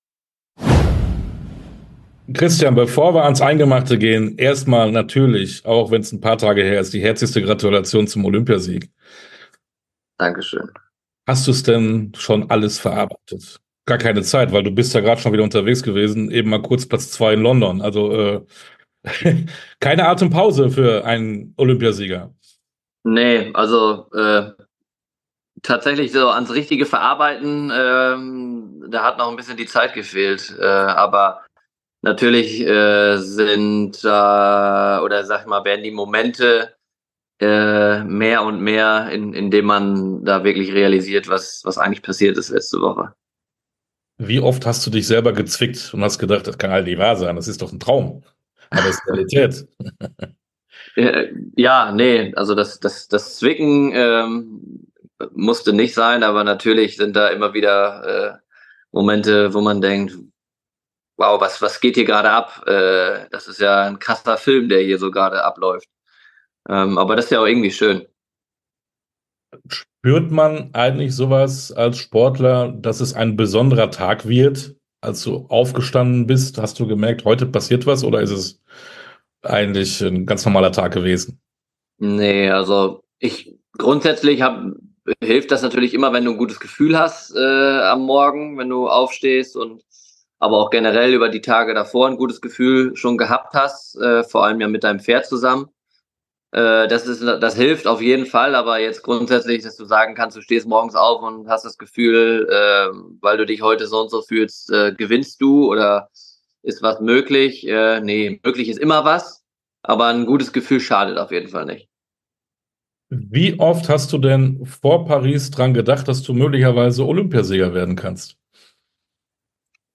Sportstunde - Interview komplett Christian Kukuk, Springreiten Olympiasieger ~ Sportstunde - Interviews in voller Länge Podcast
Interview_komplett_Christian_Kukuk-_Springreiten_-_Olympiasieger.mp3